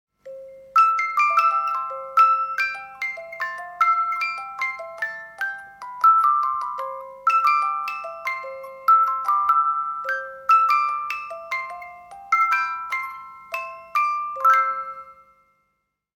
Mécanisme musical à ficelle lavable de 18 notes.